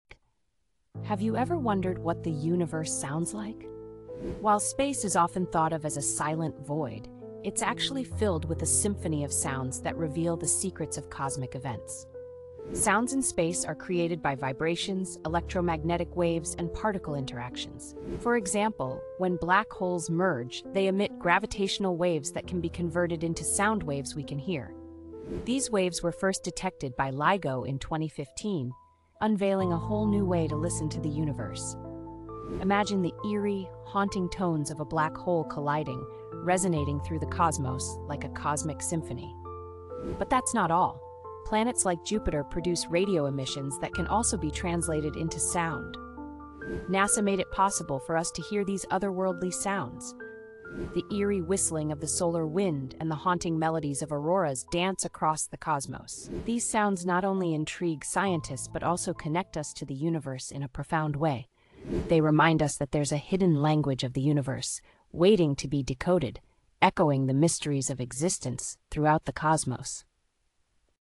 Discover the eerie and fascinating sounds of the universe.